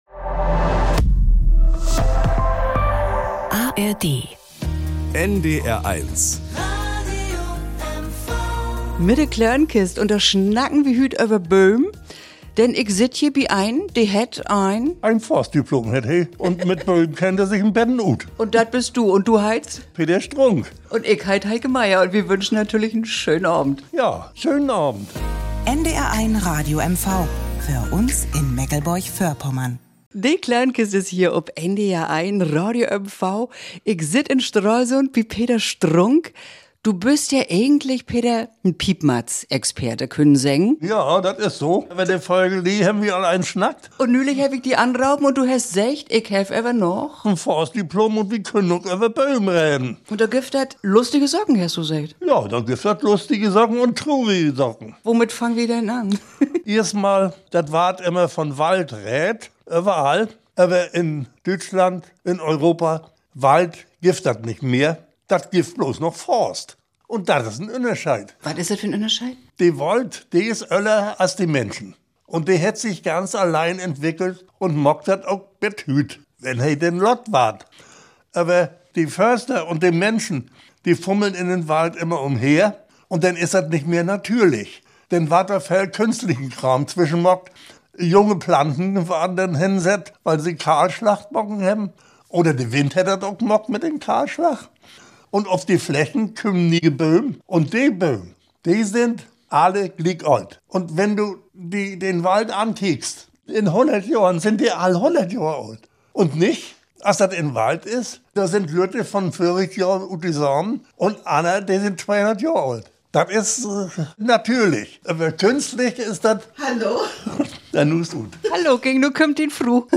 Menschen ganz nah: Gute Gespräche, hintergründig und ausführlich in "De Klönkist".